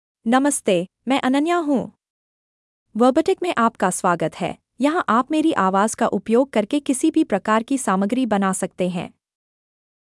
Ananya — Female Hindi (India) AI Voice | TTS, Voice Cloning & Video | Verbatik AI
FemaleHindi (India)
Voice sample
Female
Ananya delivers clear pronunciation with authentic India Hindi intonation, making your content sound professionally produced.